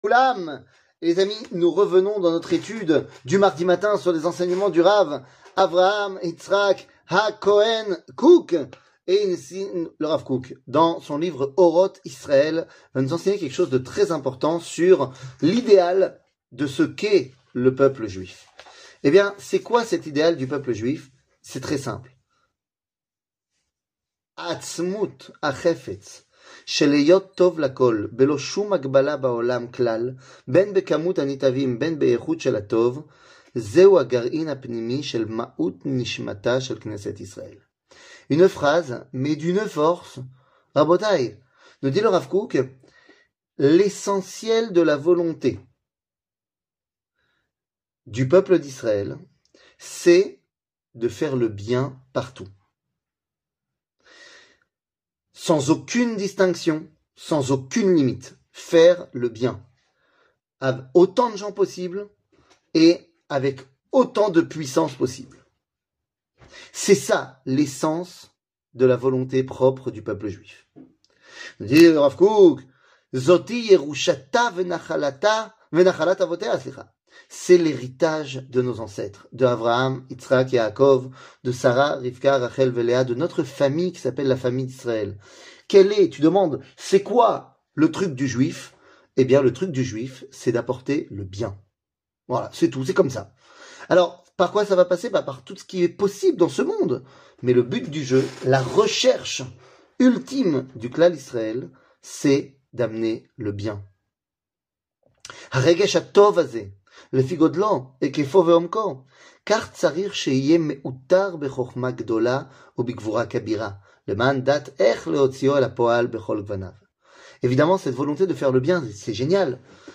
Rav Kook, Orot Israël, rechercher le bien 00:05:09 Rav Kook, Orot Israël, rechercher le bien שיעור מ 20 פברואר 2024 05MIN הורדה בקובץ אודיו MP3 (4.71 Mo) הורדה בקובץ וידאו MP4 (6.61 Mo) TAGS : שיעורים קצרים